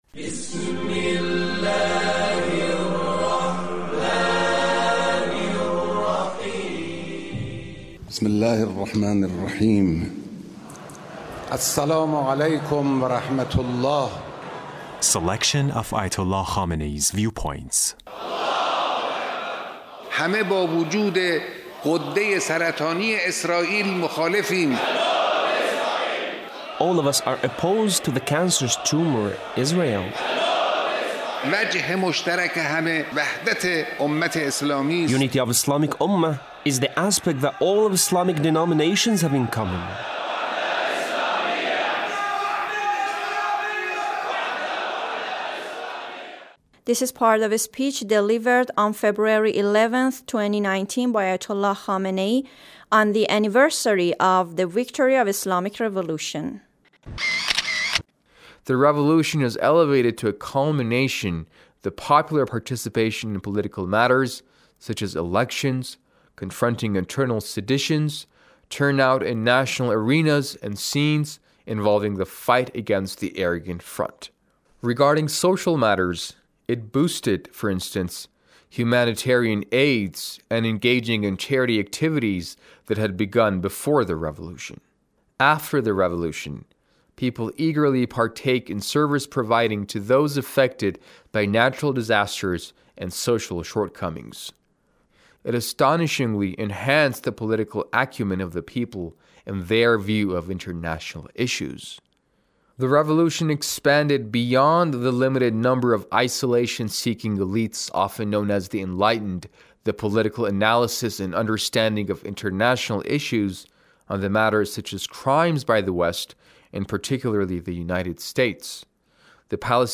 The Leader's speech on THE ANNIVERSARY OF THE ISLAMIC REVOLUTION-THE ACHIEVEMENTS OF THE REVOLUTION